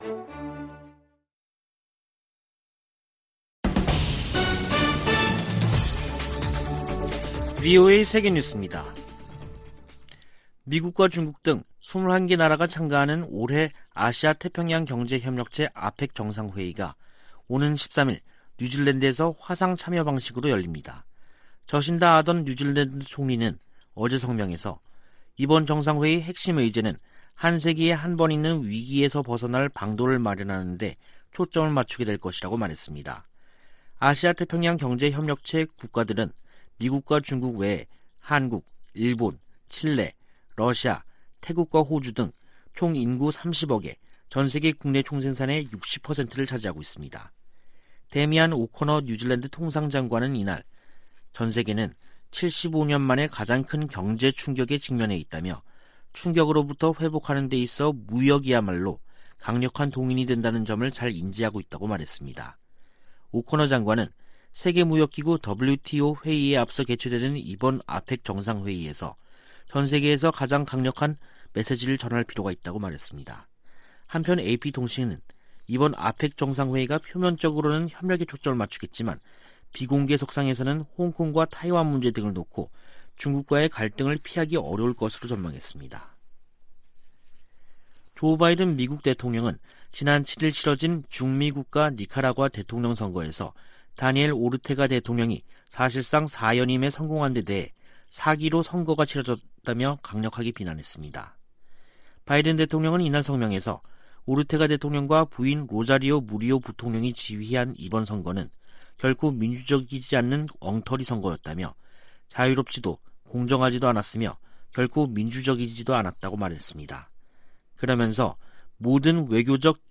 VOA 한국어 간판 뉴스 프로그램 '뉴스 투데이', 2021년 11월 9일 2부 방송입니다. 미국 민주당 상·하원 의원들이 대북 인도적 지원을 위한 규정 완화를 촉구하는 서한을 조 바이든 대통령에게 보냈습니다. 일본은 북 핵 위협 제거되지 않은 상태에서의 종전선언을 우려하고 있을 것으로 미국의 전문가들이 보고 있습니다. 최근 북-중 교역이 크게 증가한 가운데 코로나 사태 이후 처음으로 열차가 통행한 것으로 알려졌습니다.